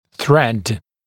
[θred][срэд]нить (в т.ч. хирургическая); резьба, нарезка